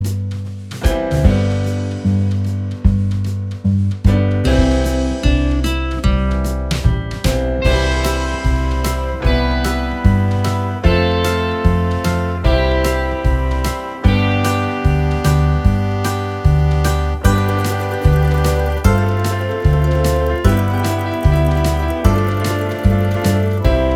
Minus Main Guitar Pop (1970s) 4:10 Buy £1.50